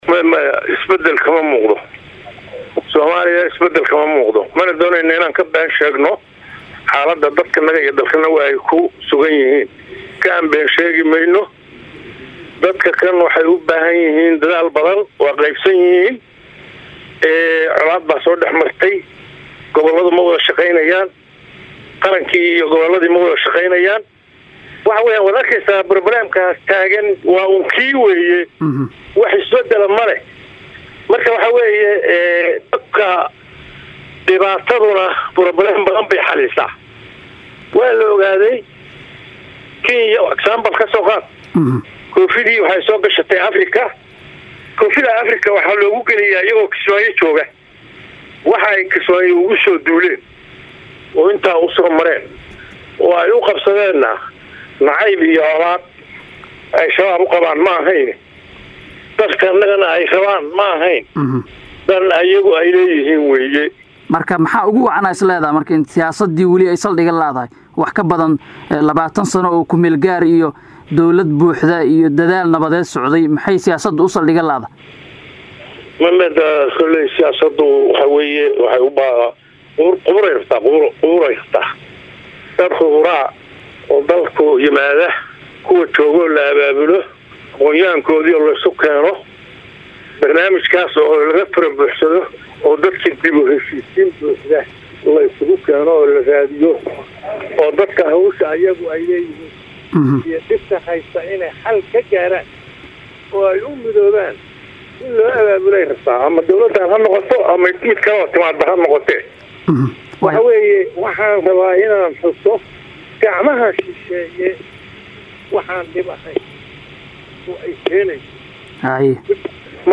Kismaayo (Caasimada Online) – Koloneyl Barre Aadan Shire (Barre Hiiraale) oo mar soo noqday wasiirkii gaashaandhigga Soomaaliya ayaa  wareysi gaar ah waxaa uu  siiyay idaacadda Kulmiye ee magaalada Muqdisho, waxaa uu kaga hadlay arrimo ay ka mid yihiin amniga, dagaalka lagula jiro Al-Shabaab iyo dhibaatada ciidamada Kenya ka geystaan dalka.
Qaybta-2-aad-Wareysiga-Barre-Hiiraale-.mp3